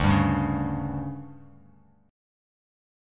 DM7sus4 chord